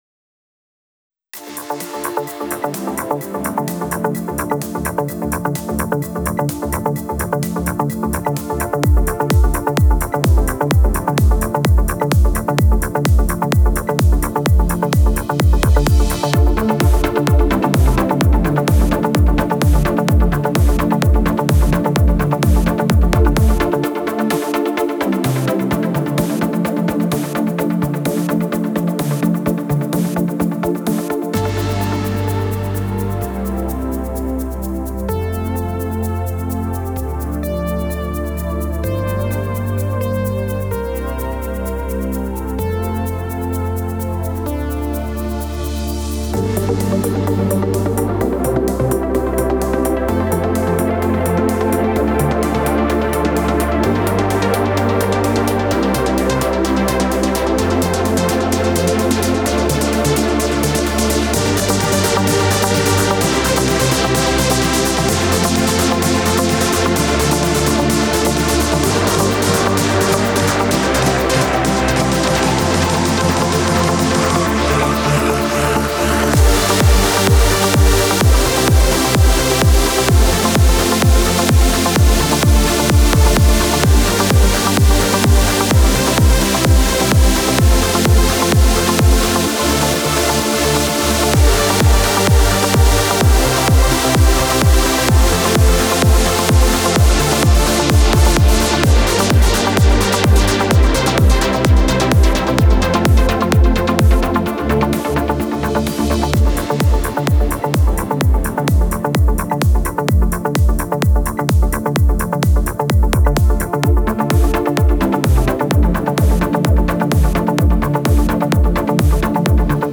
music / ELECTRO DEF